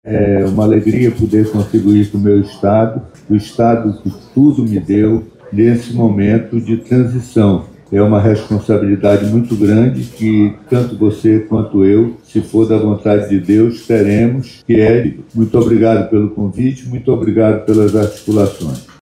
O ex-deputado estadual e ex-prefeito de Manaus, Serafim Corrêa, destacou a importância do momento político para o Amazonas.